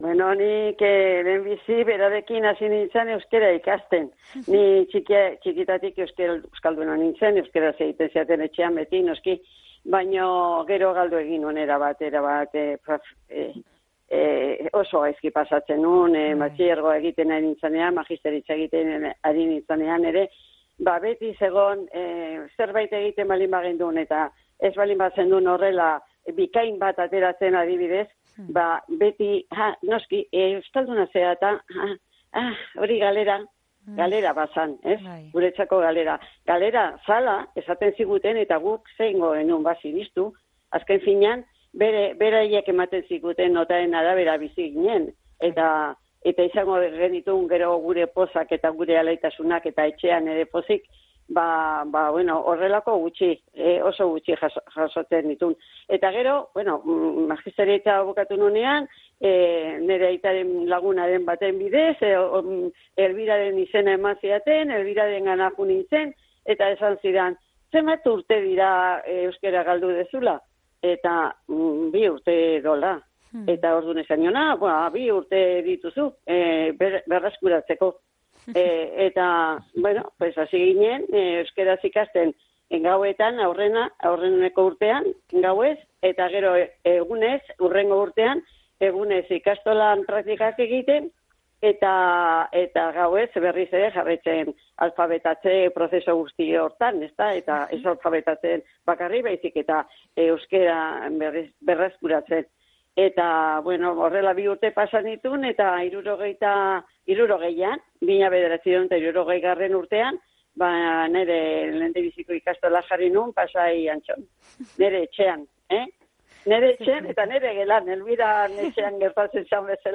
Gipuzkoako Kale Nagusian